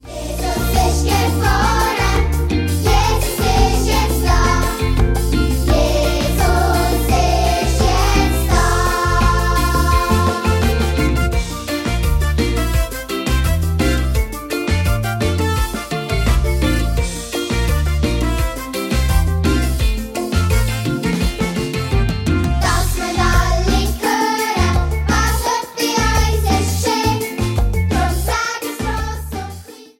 Weihnachtsmusical